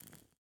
Minecraft Version Minecraft Version latest Latest Release | Latest Snapshot latest / assets / minecraft / sounds / block / candle / ambient5.ogg Compare With Compare With Latest Release | Latest Snapshot